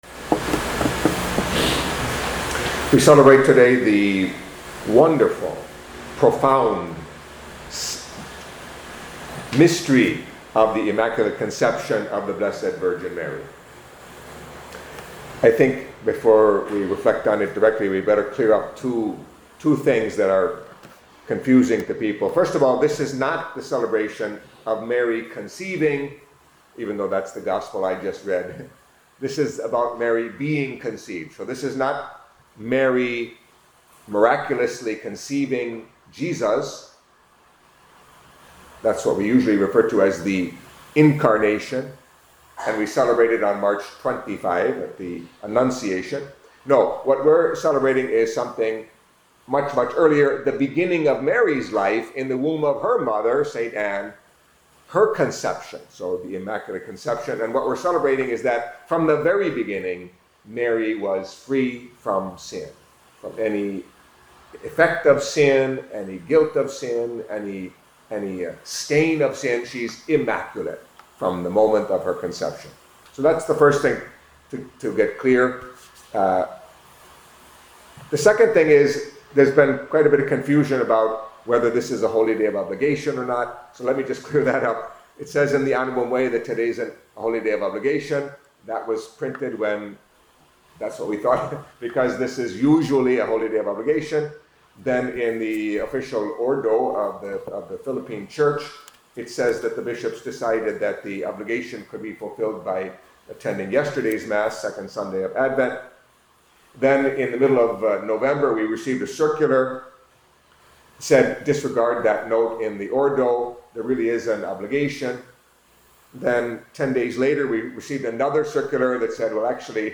Catholic Mass homily for the Solemnity of the Immaculate Conception of the Blessed Virgin Mary